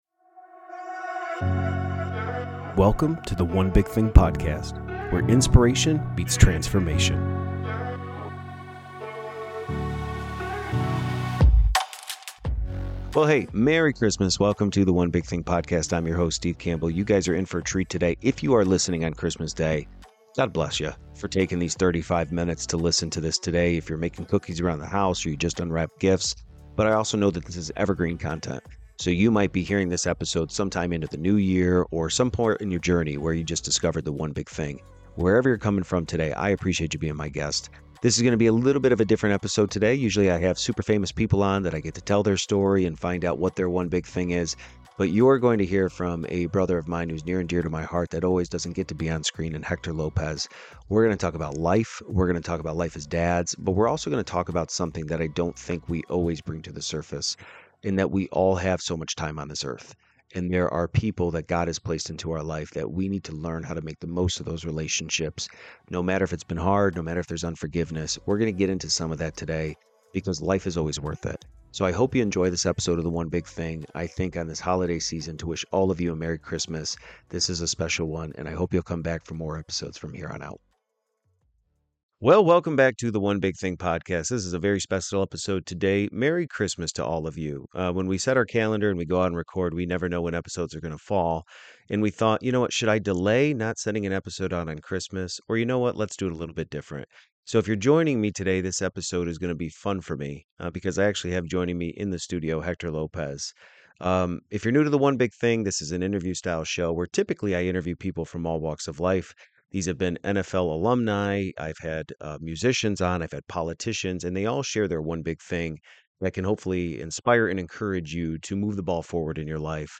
With a mix of humor and vulnerability, this episode is a reminder to cherish every moment, foster meaningful connections, and be fully present with the people who matter most. Tune in for a conversation that resonates far beyond the holiday season, offering inspiration for a more intentional and connected life.